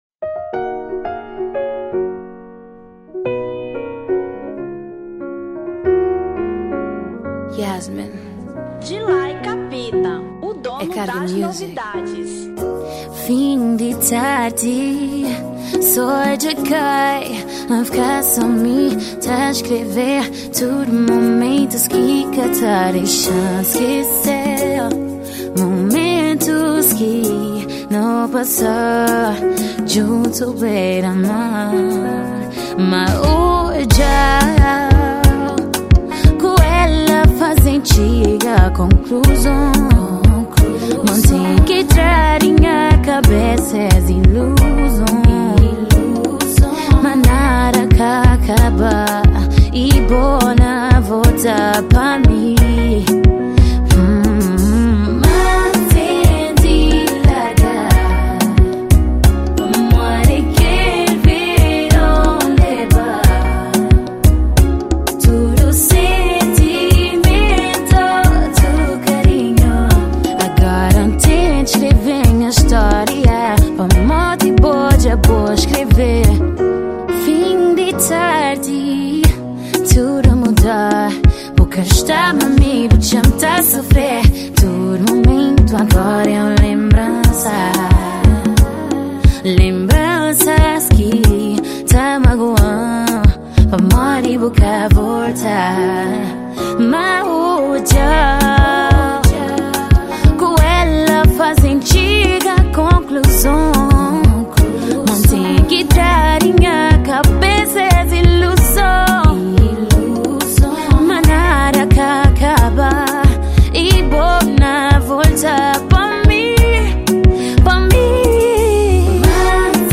Kizomba 2018